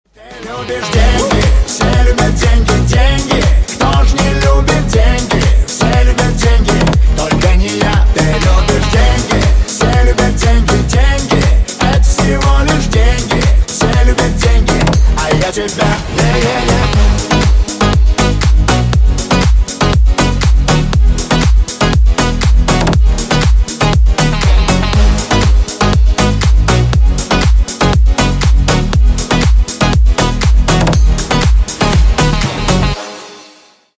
Клубные Рингтоны
Рингтоны Ремиксы » # Танцевальные Рингтоны